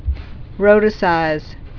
(rōtə-sīz)